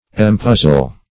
empuzzle - definition of empuzzle - synonyms, pronunciation, spelling from Free Dictionary
Search Result for " empuzzle" : The Collaborative International Dictionary of English v.0.48: Empuzzle \Em*puz"zle\, v. t. [Pref. em- + puzzle.]